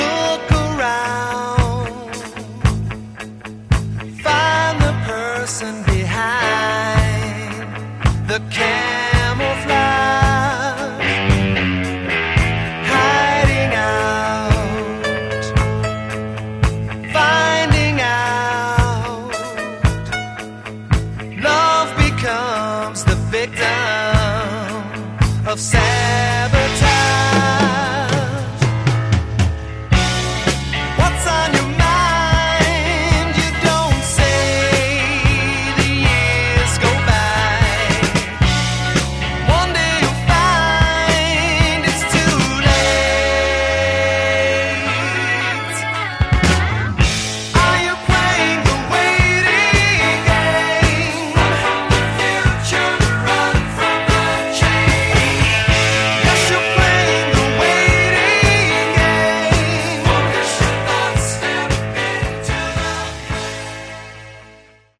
Category: AOR
vocals, bass
keyboards, guitar
drums